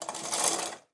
Shower Curtain Open Sound
household
Shower Curtain Open